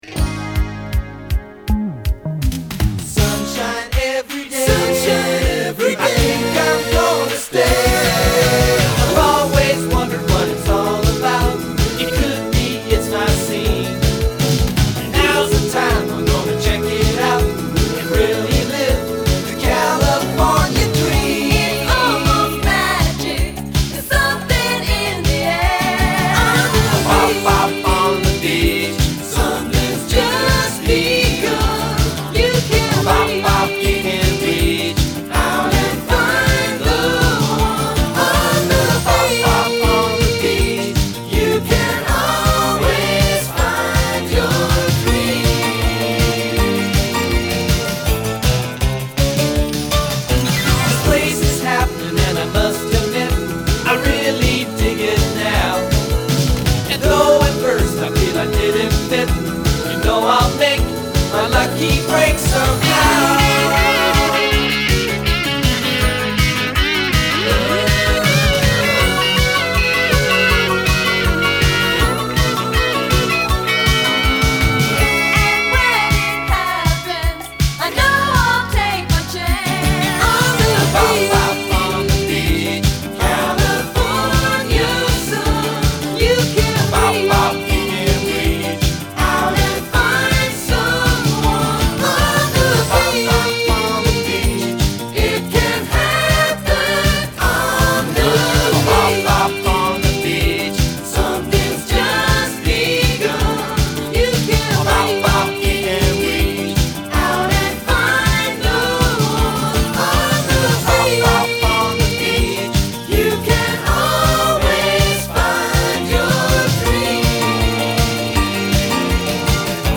I ripped it from my original vinyl copy this very morning.